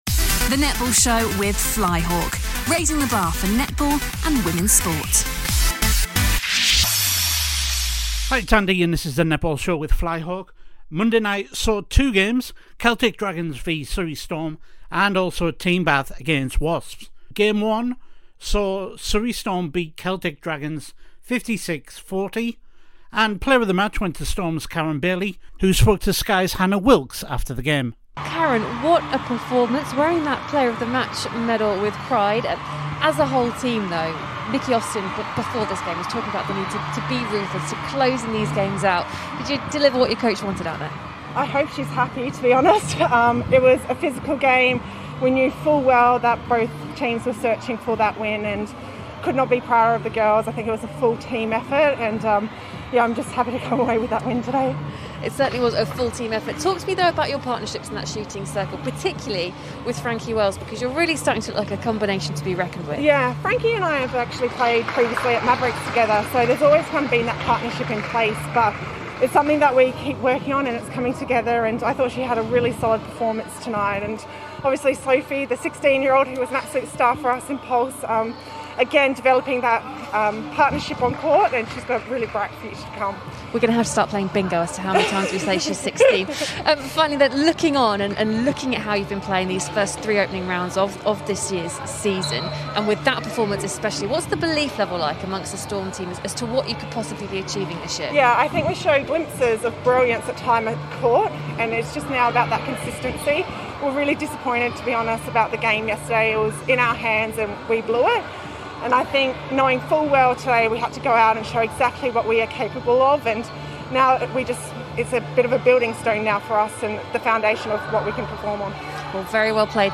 Sky Sports Postmatch interviews are used with their permission